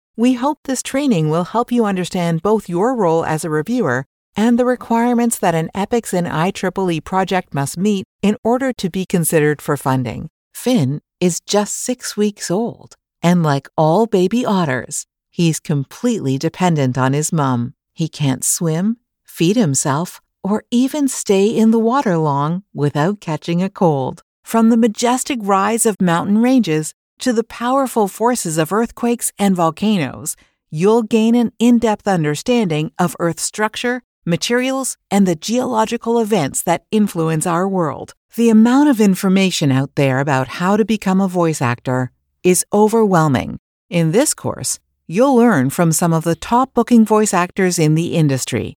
E-Learning demo
My voice has been described as a “warm hug".
e-learning-demo.mp3